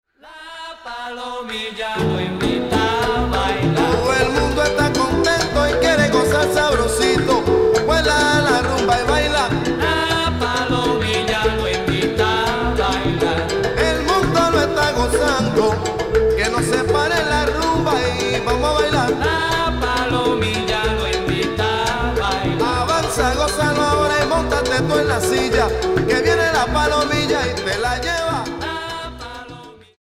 Salsa Charts - Februar 2011